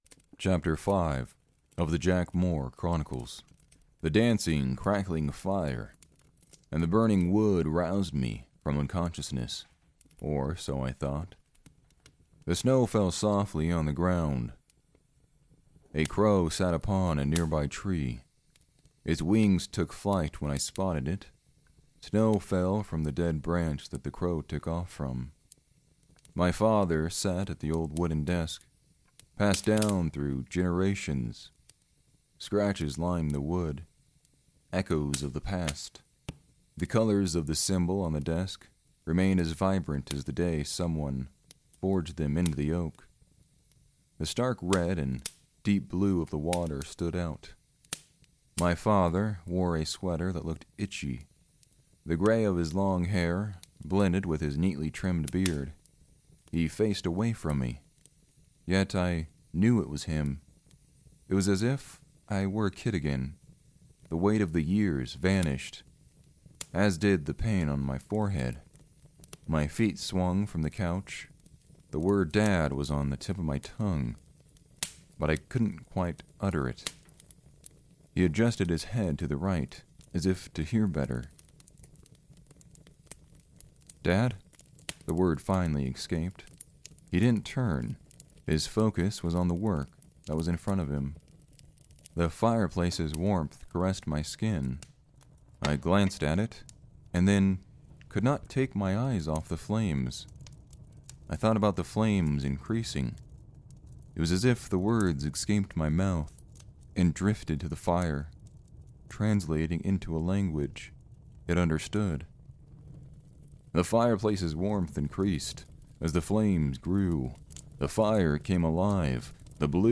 A Gritty Noir Fantasy Fiction Podcast Series